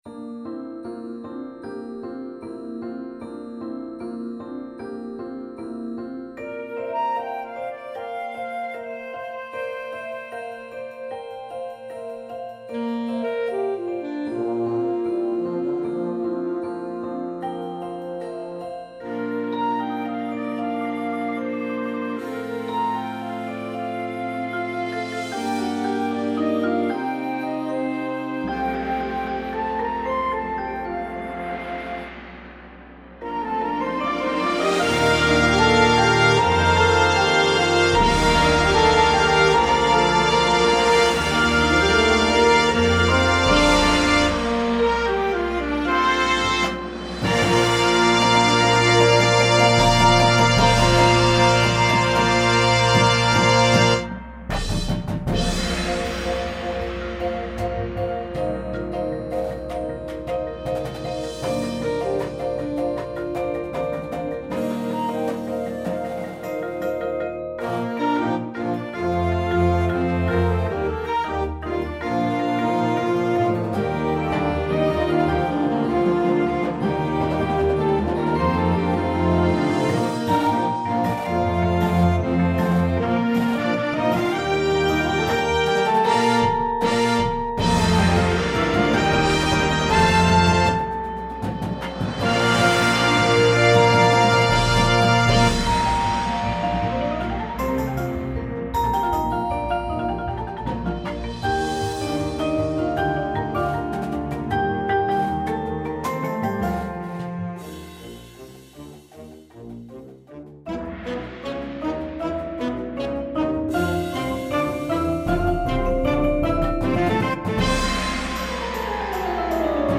• Flute
• Trombone 1, 2
• Snare Drum